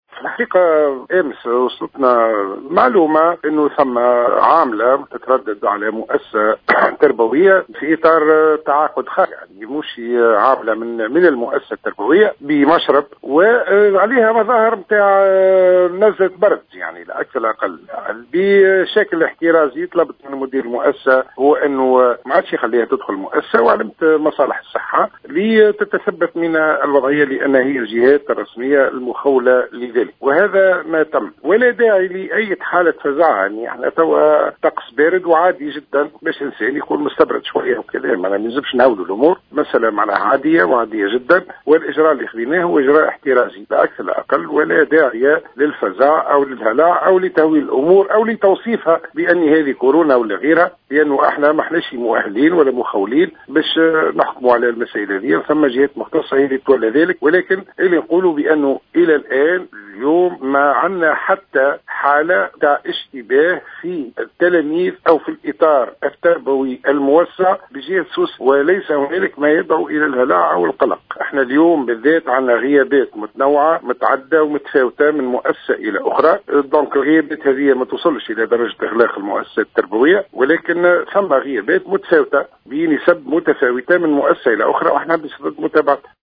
وأوضح الزبيدي في تصريح للجوهرة أف أم، أن المندوبية الجهوية طلبت، بشكل احترازي من إدارة إحدى المؤسسات التربوية بالقلعة الصغرى، منع عاملة بالمشرب من دخول المؤسسة، بعد أن ظهرت عليها أعراض الإصابة بنزلة برد عادية، تفاديا لكل ما من شأنه نشر الفزع في صفوف كافة مكونات الأسرة التربوية.